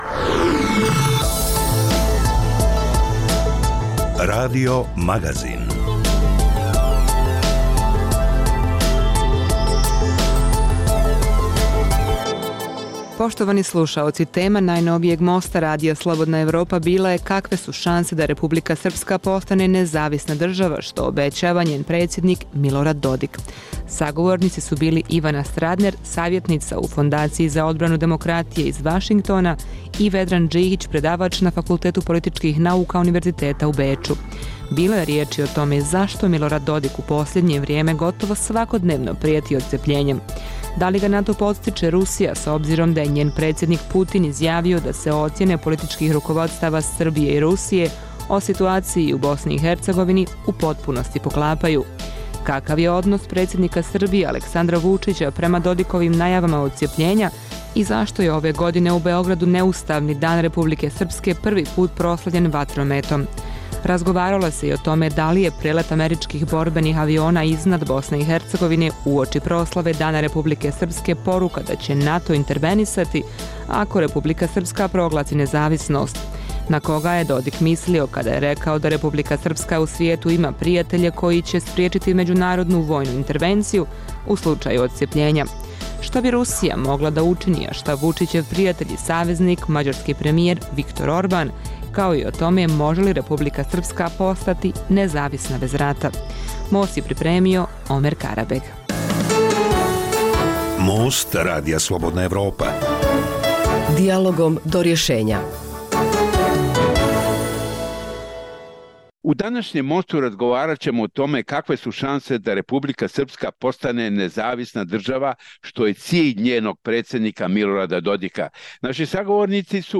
Dijaloška emisija o politici, ekonomiji i kulturi.